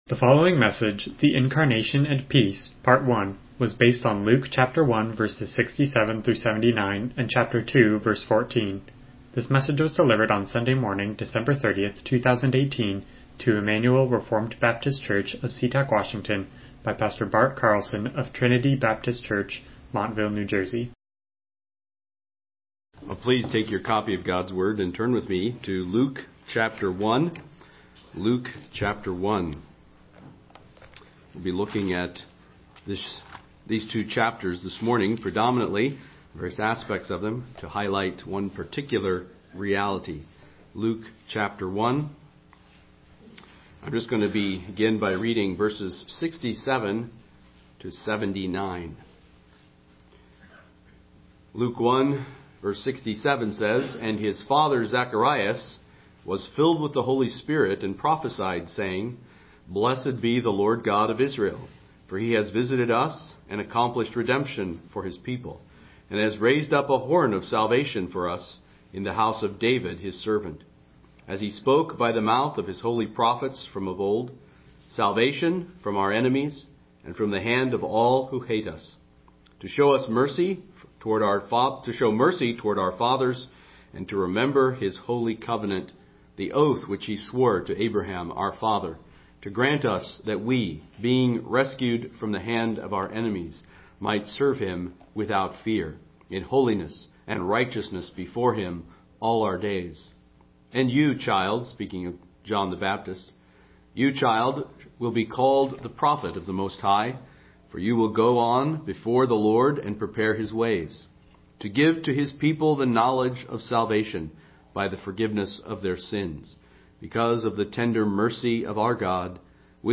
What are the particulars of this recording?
Luke 2:14 Service Type: Morning Worship « Leviticus The Incarnation and Peace